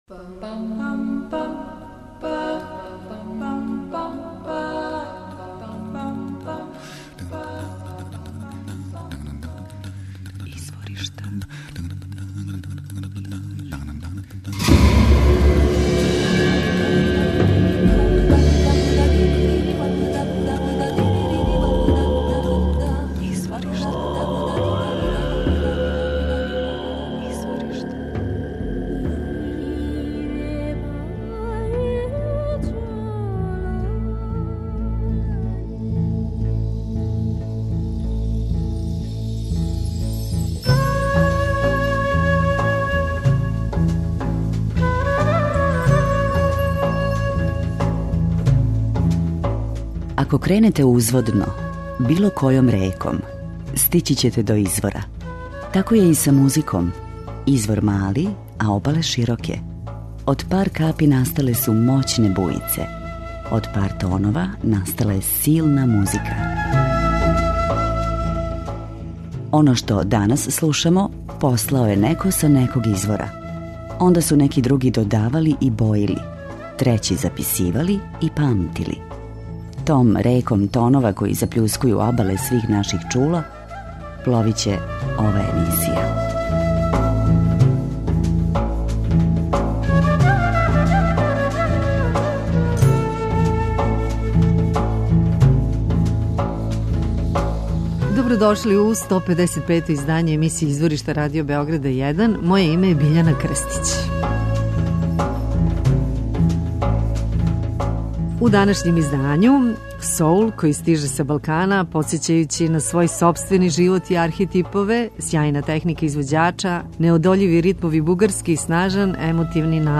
Соул
фузија џеза и бугарског народног мелоса.